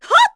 Laudia-Vox_Attack3_kr.wav